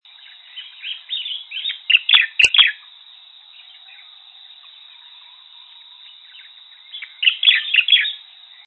10-4柴山白頭may30-1.mp3
白頭翁(台灣亞種) Pycnonotus sinensis formosae
錄音地點 高雄市 鼓山區 柴山
錄音環境 雜木林
行為描述 雄鳥鳴唱
收音: 廠牌 Sennheiser 型號 ME 67